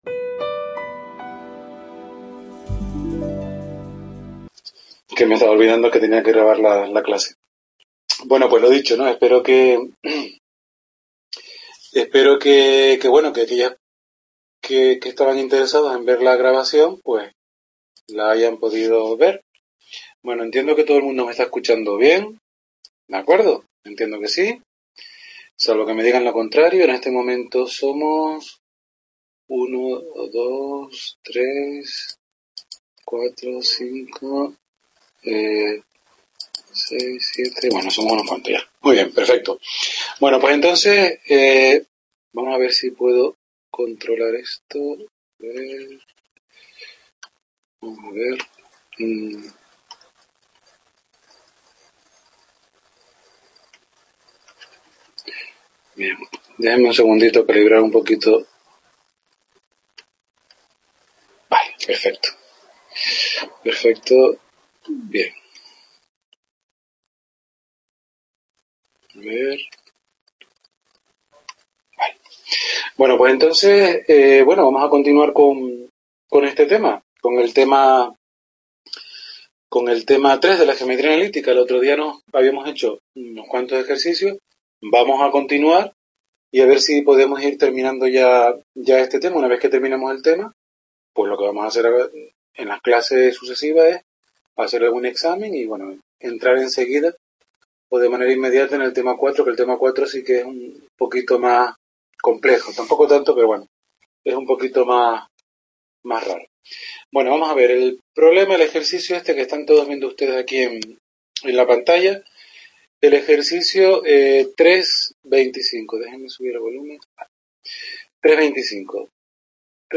Tercera clase de geometría analítica.